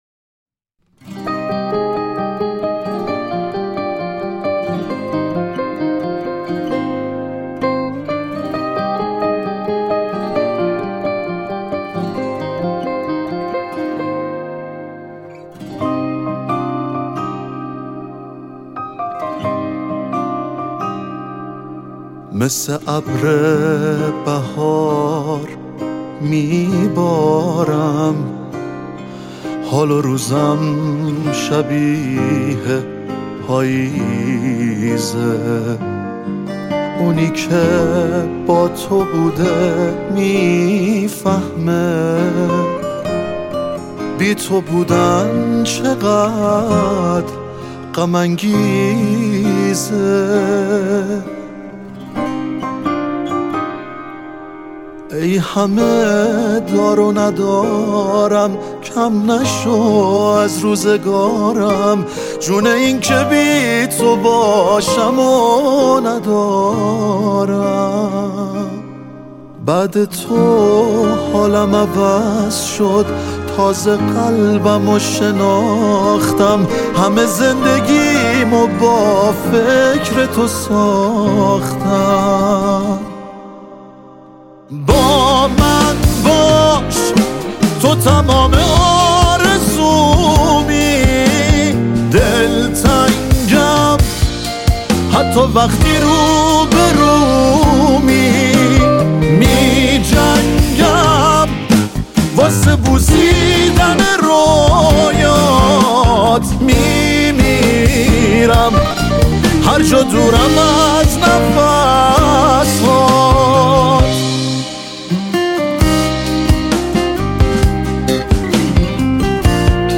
گیتار آکوستیک
گیتار الکتریک